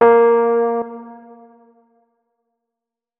keyboard / electric_piano